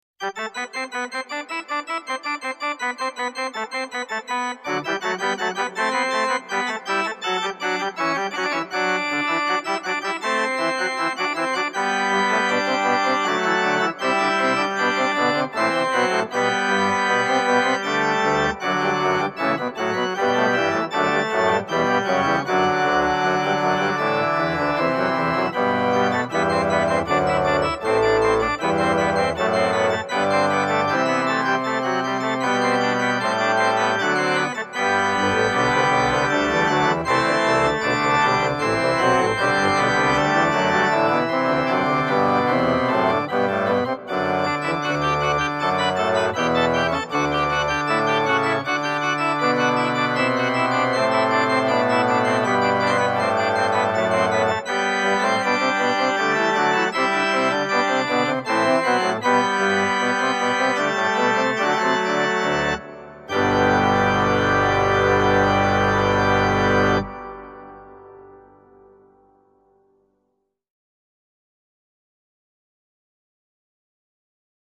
Viscount expander (rozšiřující modul) digitální varhany
Hlasy expanderu CM100, díky Accupipe Technology, jsou vytvářeny a ne samplovány, díky čemuž jsou zachovány veškeré detaily zvuku klasických varhan, jako je zabarvení, nasazení a mnoho dalších.
Ukázka zvuku 7 (mp3)